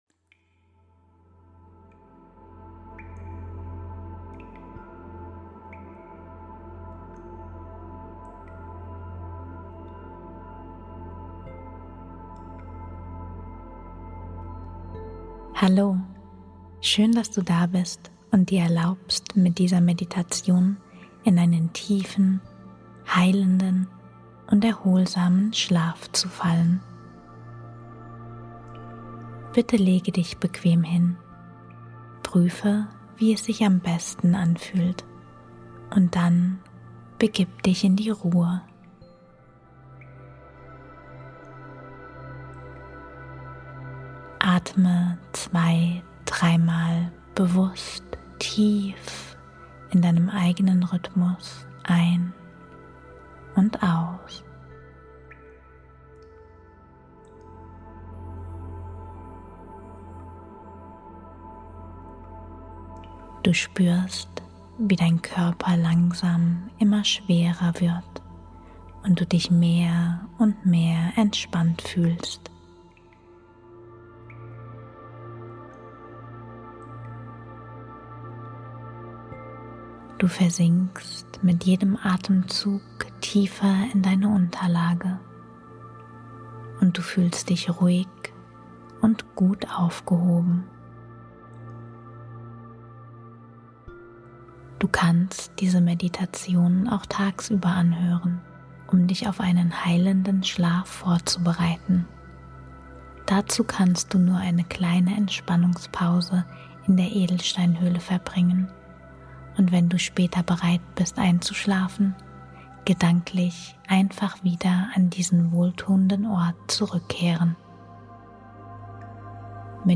Energie-Motivations-Rakete - 100 Morgen-Affirmationen mit 285Hz